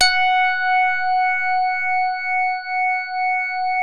JAZZ HARD#F4.wav